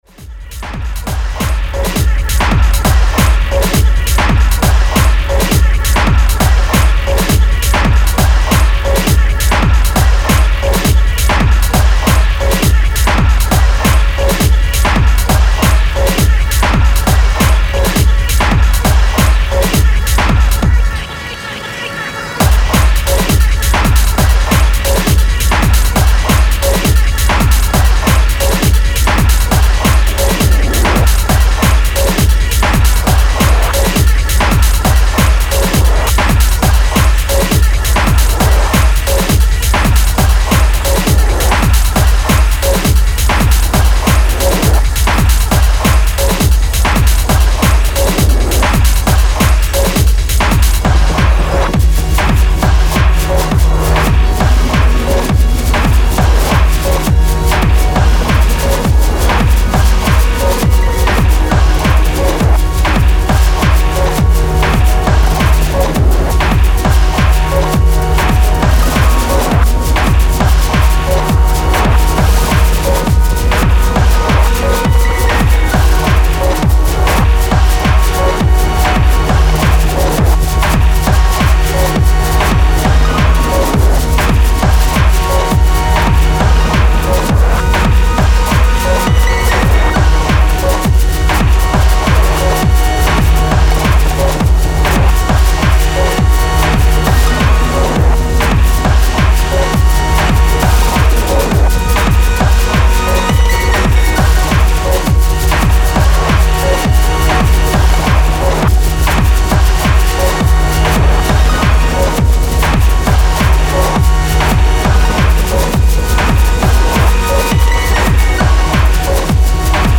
Powerful techno tracks